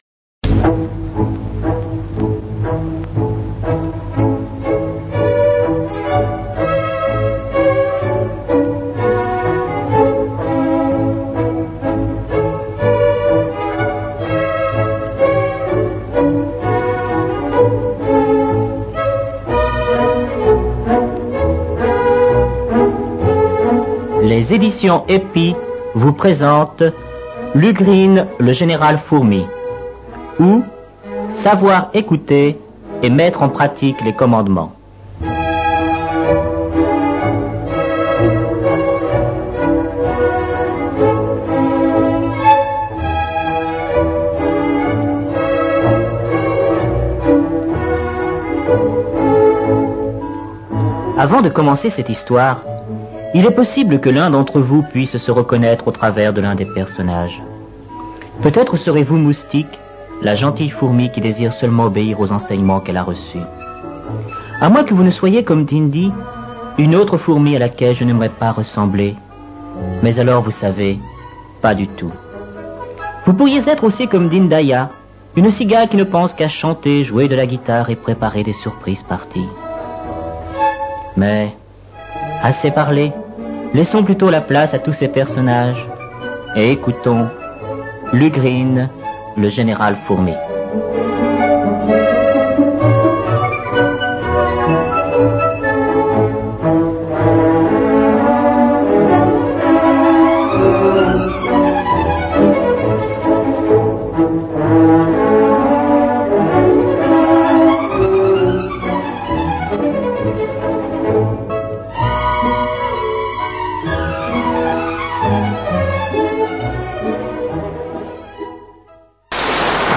Histoire pour enfants (Septembre 1983) 40 minutes à vivre au plein coeur de la forêt avec différentes fourmis sur le thème de : " savoir écouter et mettre en pratique les commandements ".